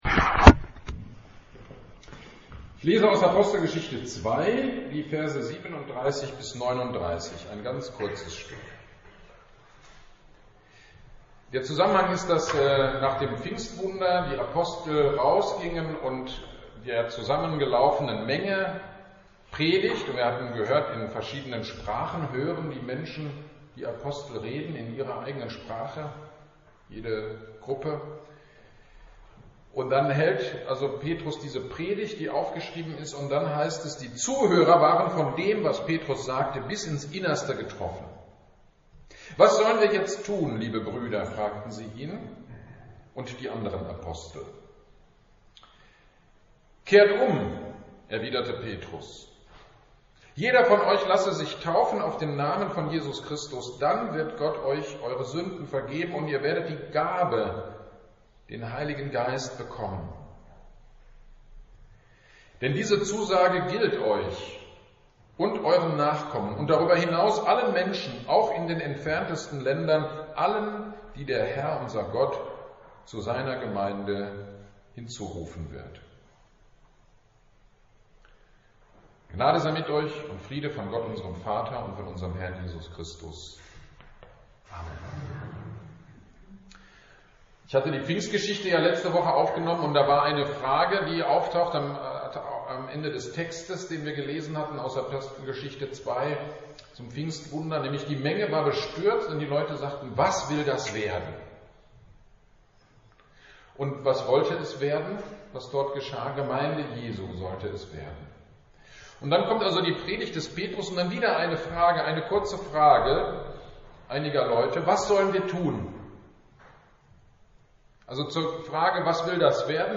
Trinitatis-Fest Predigt zu Apostelgeschichte 2/37-39 - Kirchgemeinde Pölzig